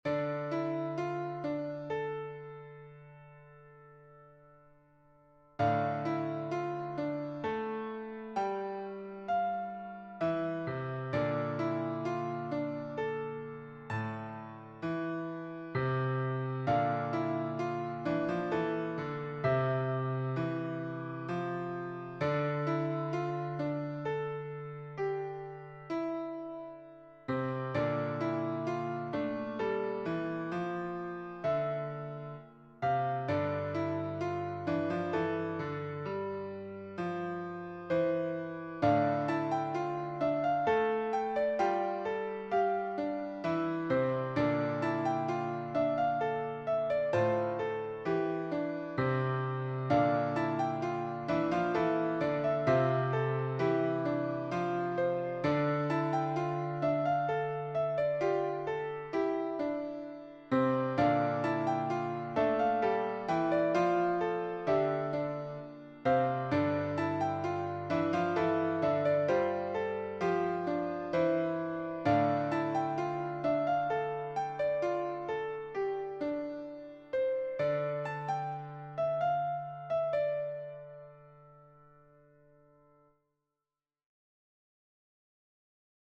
SSAATBB choir